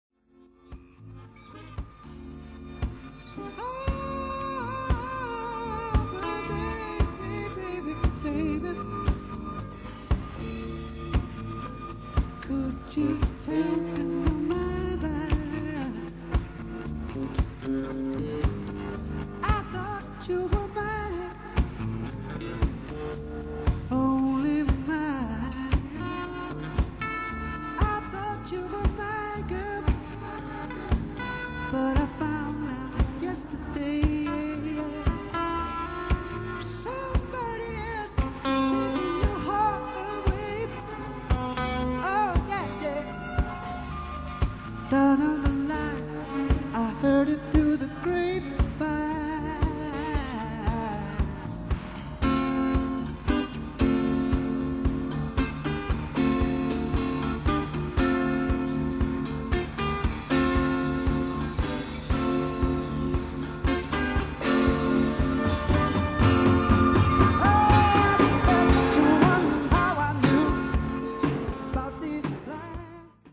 This one is the original untouched cassette recording,